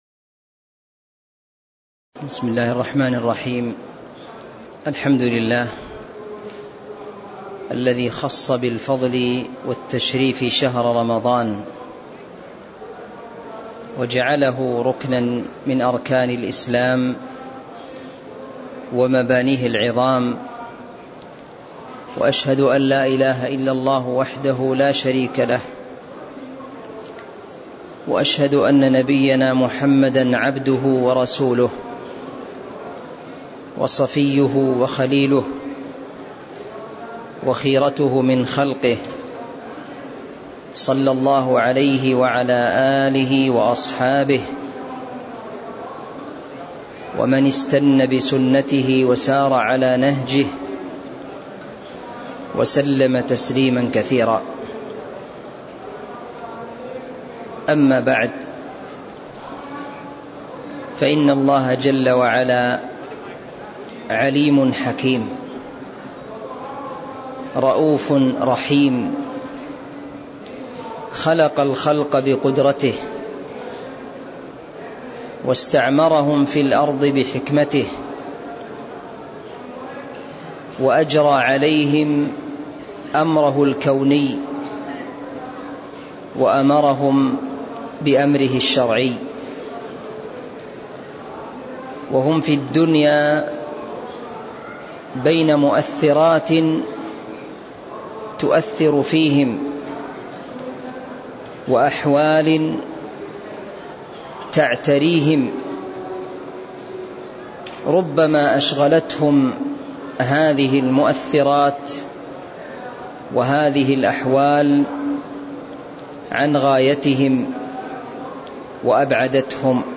تاريخ النشر ٢٨ شعبان ١٤٤٤ هـ المكان: المسجد النبوي الشيخ